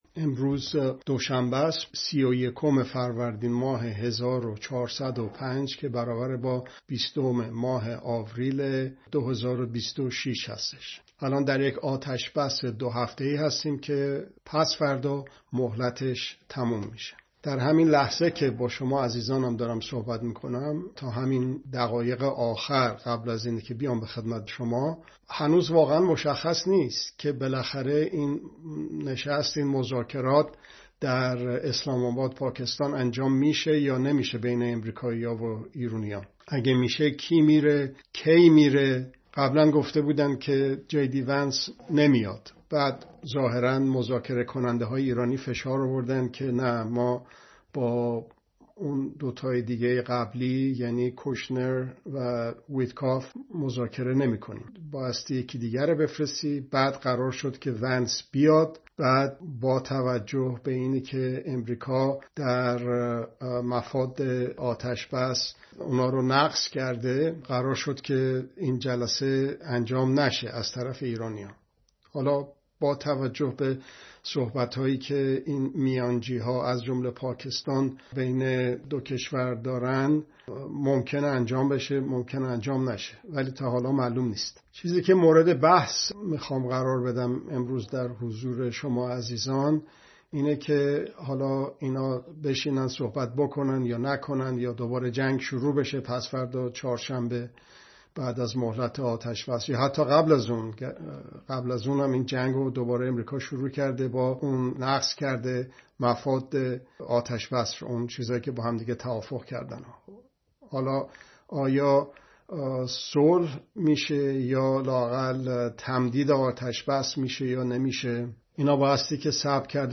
( دوشنبه‌ها ساعت۲۱ به‌وقت ایران به‌صورت زنده )